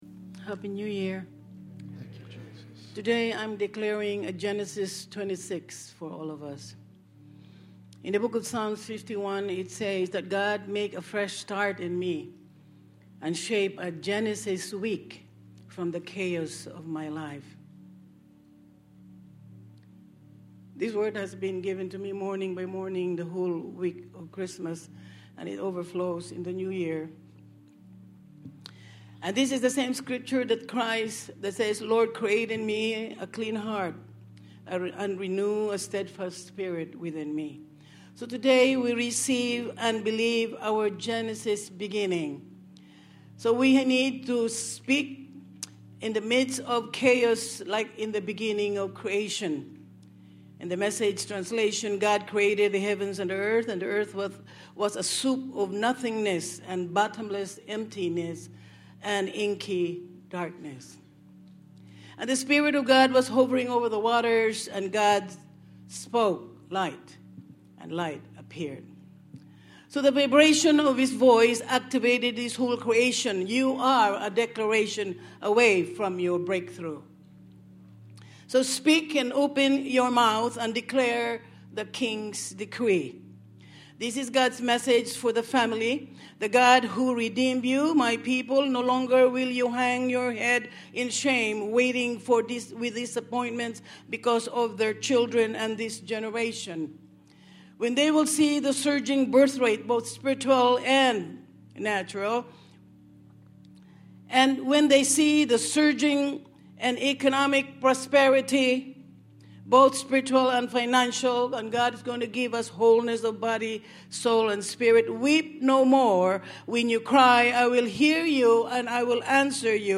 Sermon Series: God Given Benefits/Gifts